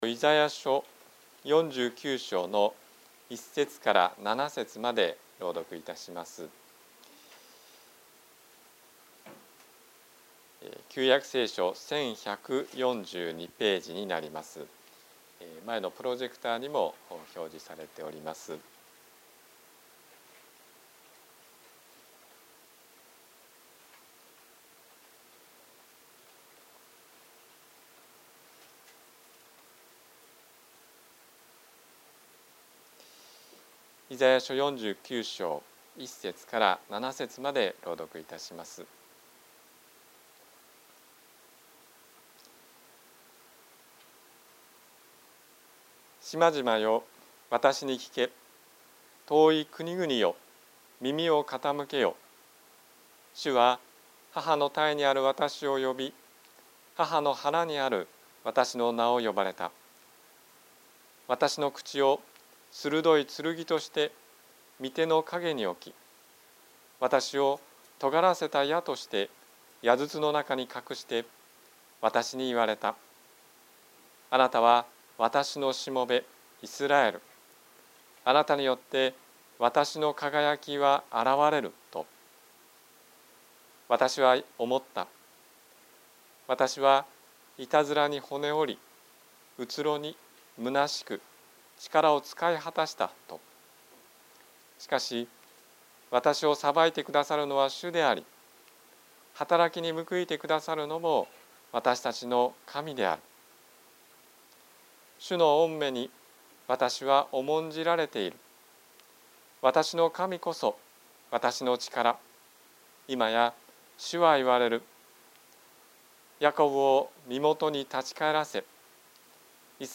宝塚の教会。説教アーカイブ。
日曜 朝の礼拝